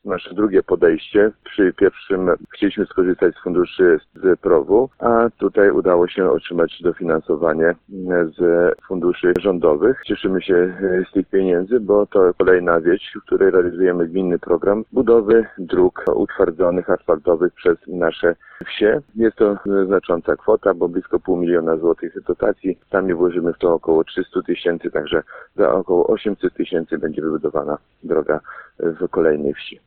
– To bardzo dobra informacja – nie ukrywa radości włodarz gminy Orzysz.